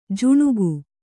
♪ juṇugu